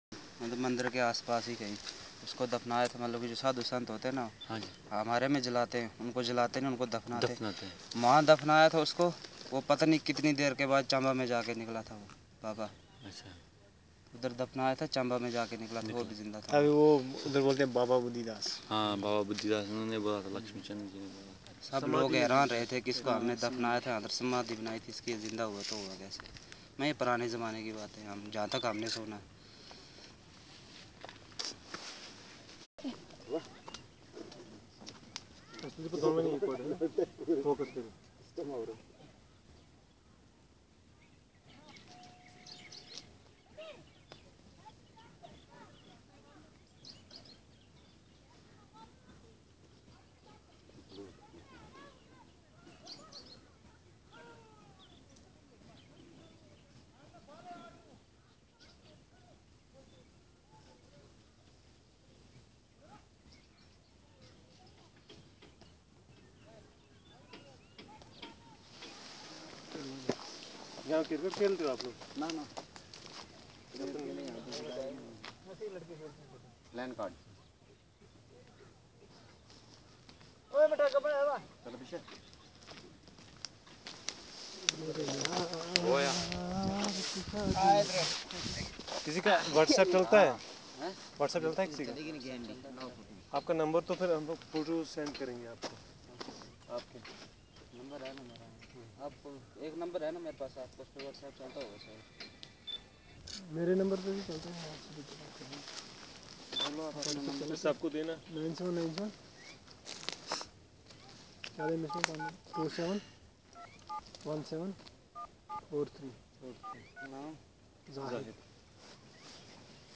Conversation about stories - Part 3